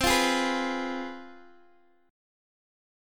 CM7sus4#5 chord